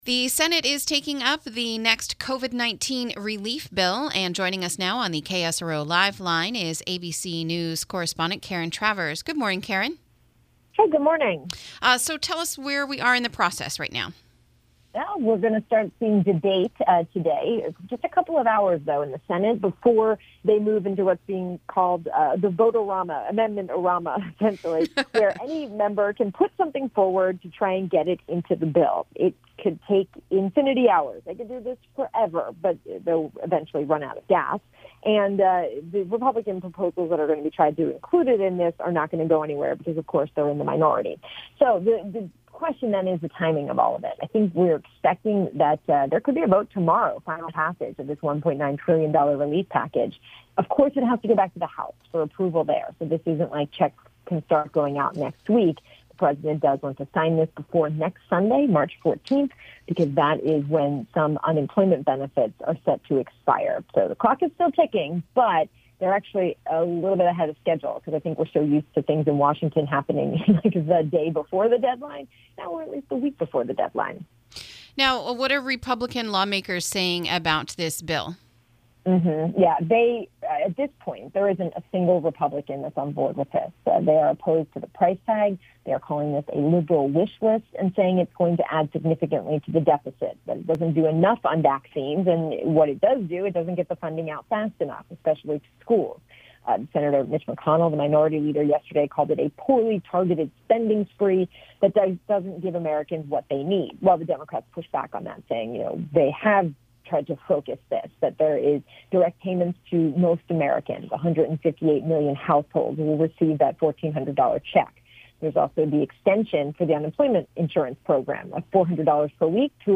INTERVIEW: Senate Debates the New Stimulus Package